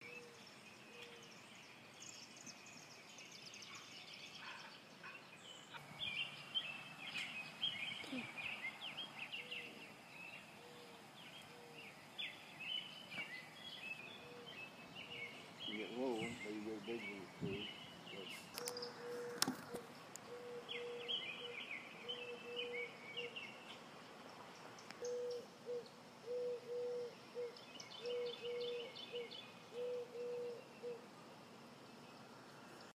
描述：在六月的一个春天的早晨，在河流和溪流之间设置我的Zoom H4n。
Tag: 鸟鸣声 春天 自然 现场录音